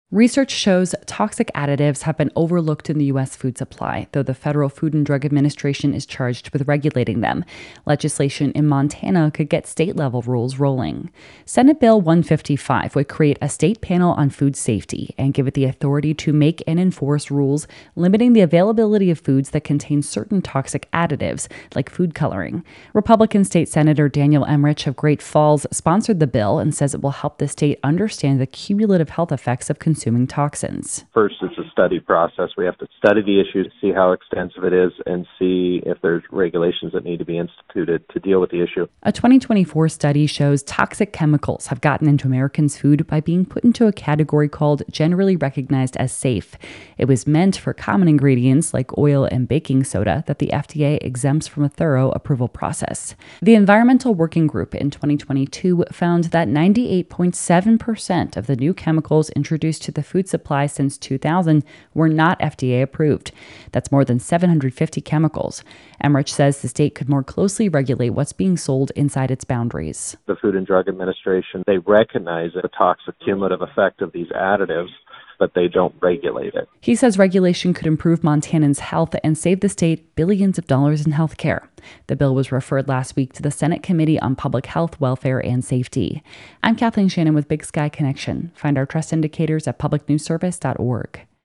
Big Sky Connection - A Montana Senate bill would create a panel on food safety to study and regulate foods that contain certain toxic chemicals, which research shows have been categorically bypassed by the federal Food and Drug Administration, through a loophole. Comments by state Sen. Daniel Emrich [EMM-ritch], R-Great Falls.